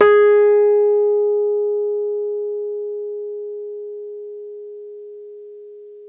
RHODES CL0DL.wav